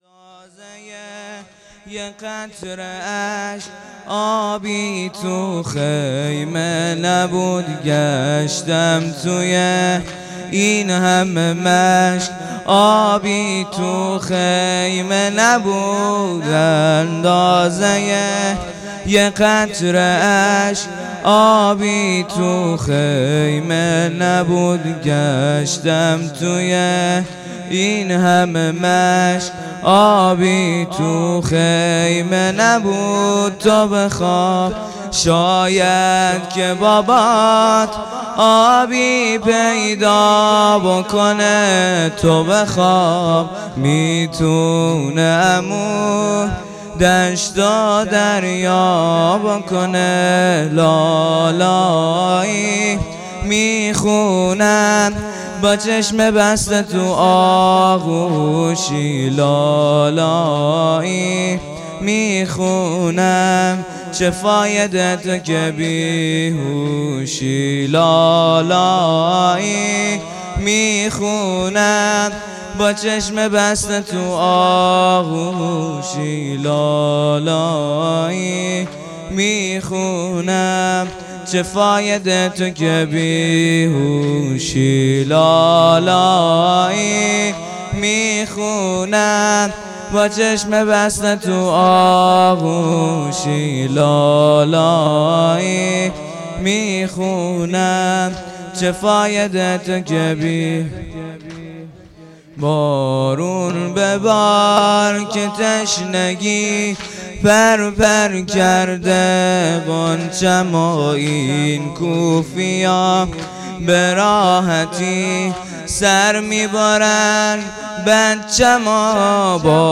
منتخب مراسم دهه اول محرم۹۹
شب هفتم محرم۹۹